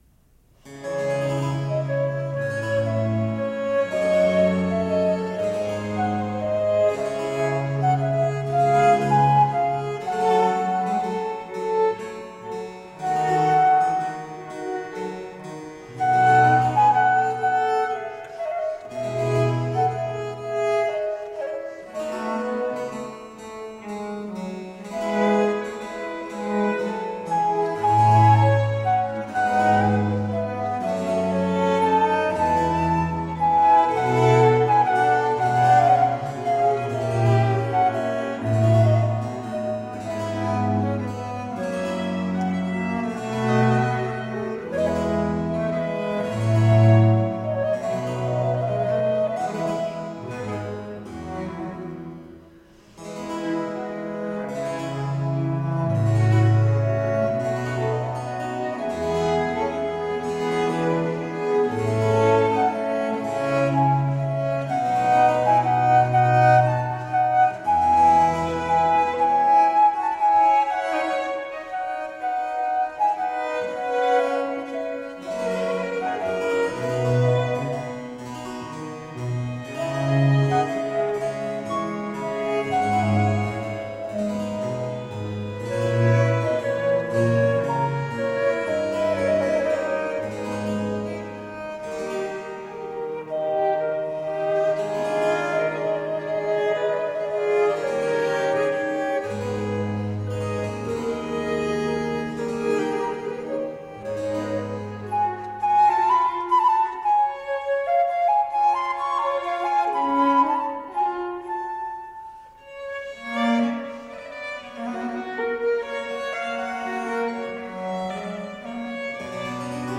Rare and extraordinary music of the baroque.
lightly elegant dance music
violinist
flutist
harpsichord
violoncello